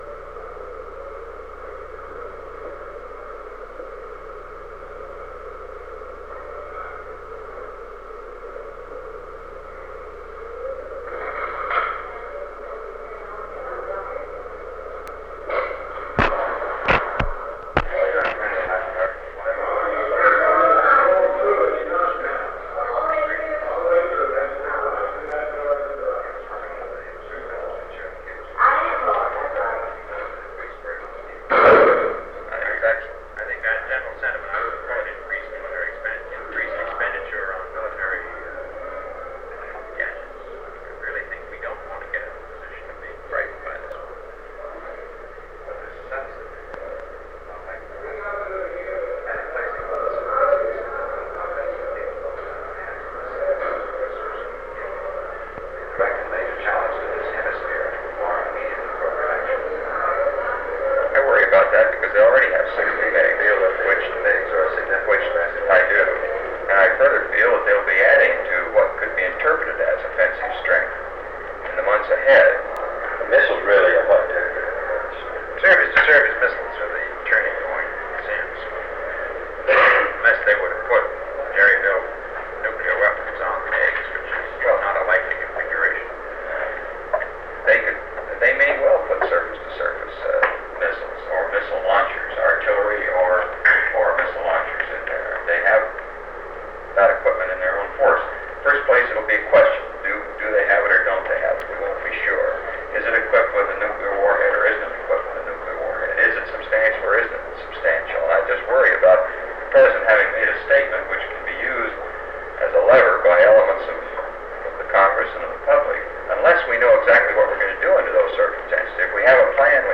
Secret White House Tapes | John F. Kennedy Presidency Meeting on Soviet Arms Shipments to Cuba Rewind 10 seconds Play/Pause Fast-forward 10 seconds 0:00 Download audio Previous Meetings: Tape 121/A57.